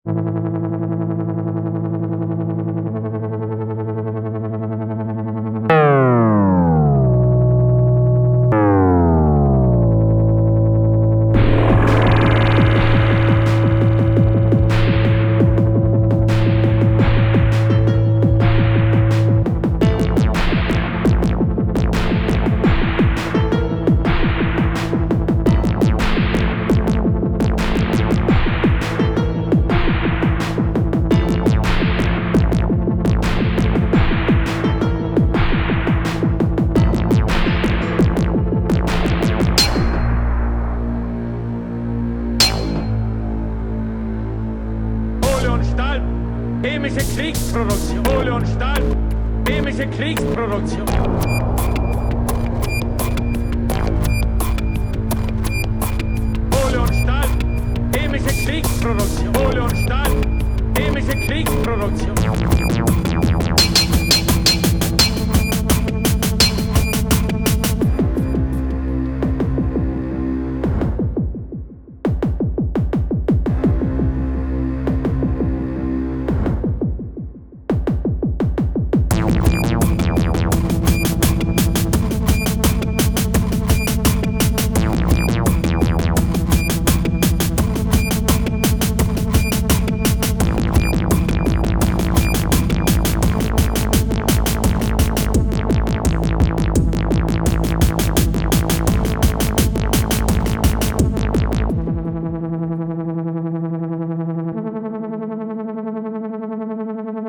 just an attempt to see if I could rip off command and conquer red alert 1's music style XDwhile it doesn't sound exactly like it, I still find this a good song.